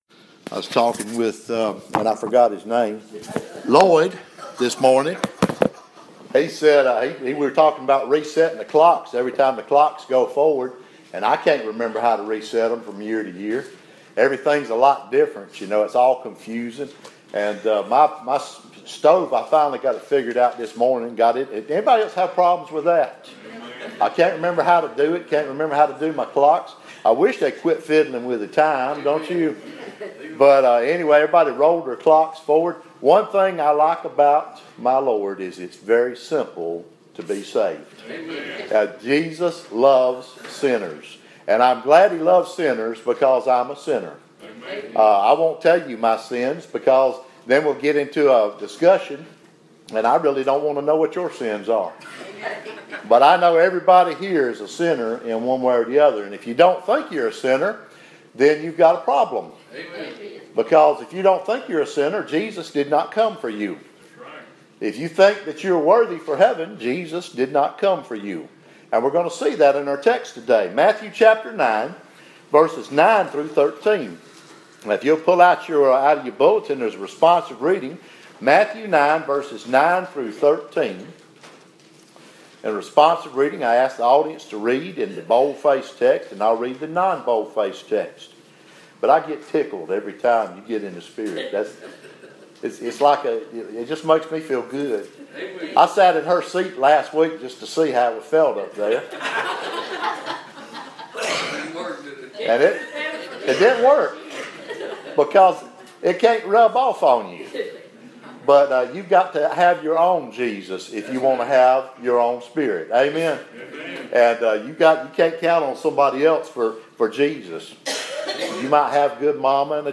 Preached to the Saints at Riverview Baptist, 798 Santa Fe Pike, Columbia TN on March 12, 2017